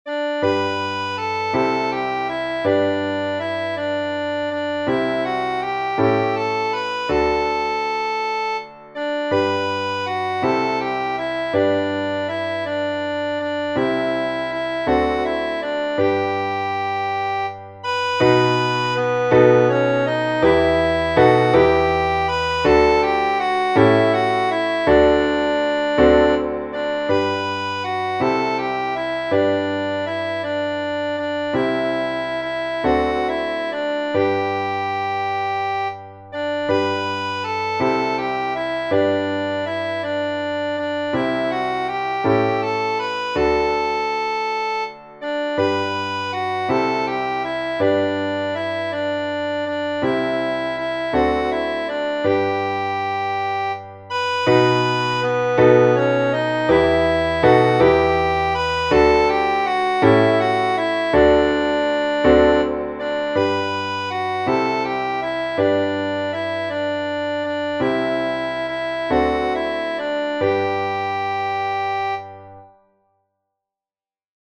Willis, R. S. Genere: Religiose " It Came Upon the Midnight Clear " è una poesia e un canto natalizio del 1849 scritto da Edmund Sears, pastore della Chiesa unitaria del Massachusetts.